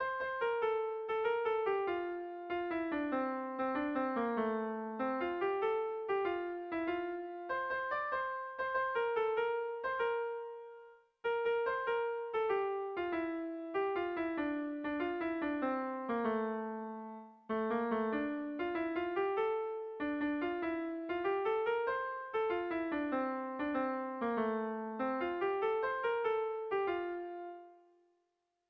Kontakizunezkoa
Bederatzikoa, handiaren moldekoa, 6 puntuz (hg) / Sei puntukoa, handiaren moldekoa (ip)
ABDEF..